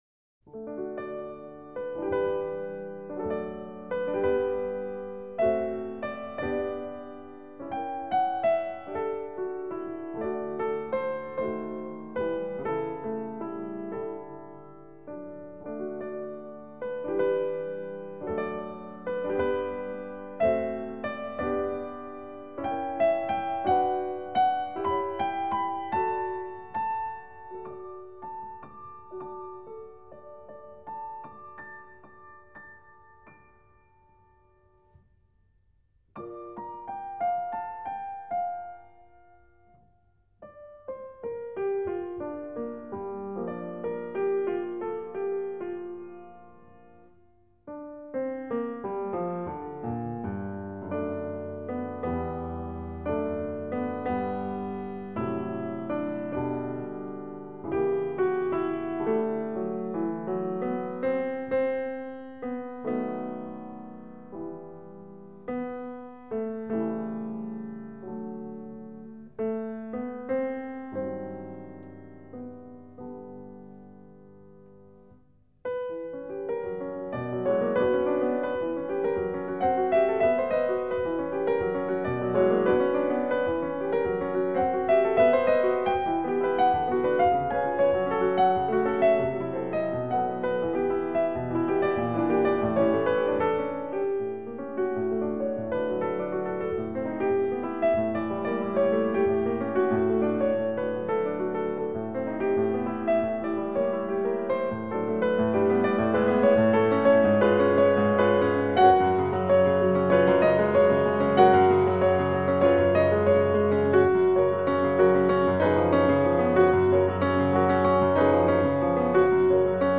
Музыкальное сопровождение (